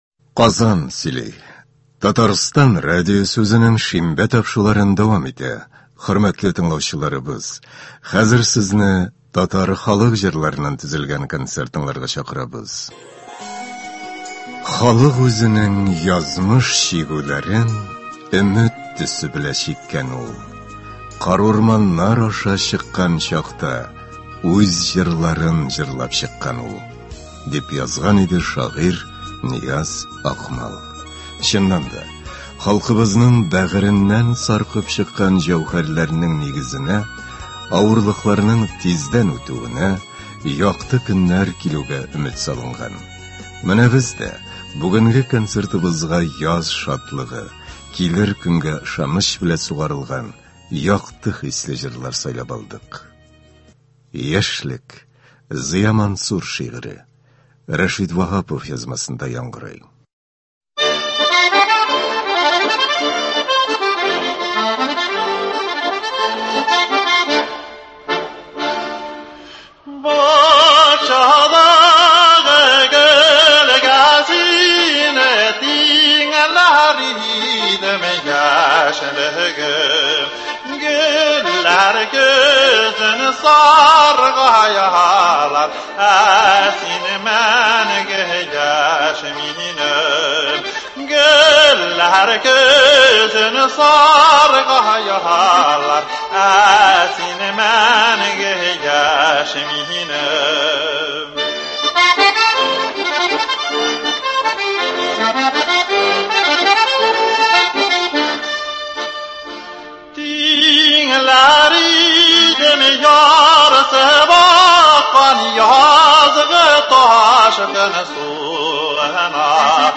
Көндезге концерт.
Татар халык көйләре (15.10.22)